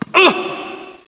uhh.wav